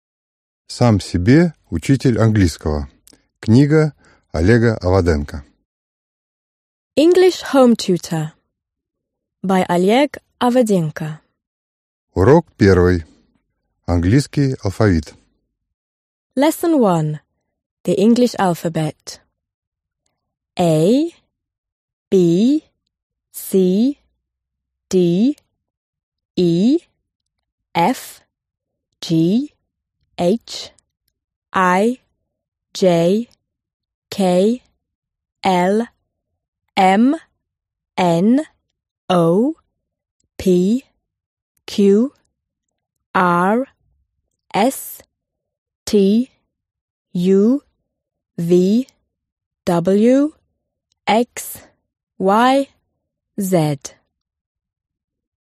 Аудиокнига Сам себе учитель английского | Библиотека аудиокниг
Прослушать и бесплатно скачать фрагмент аудиокниги